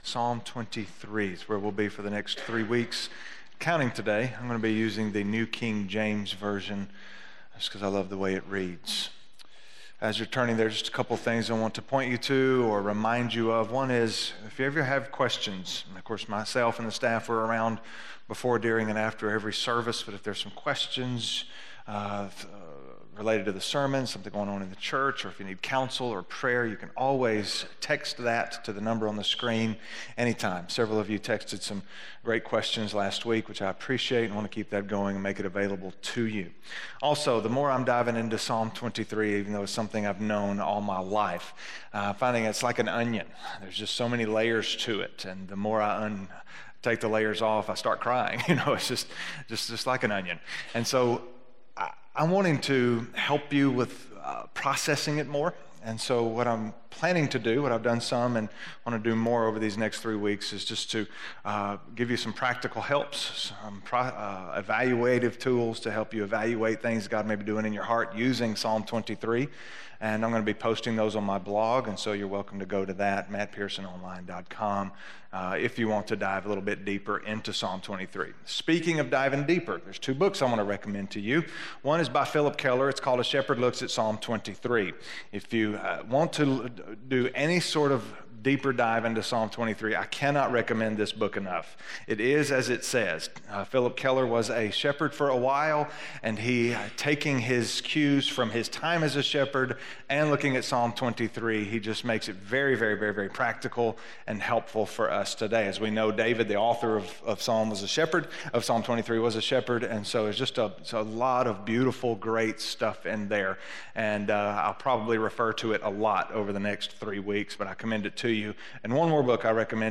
Scripture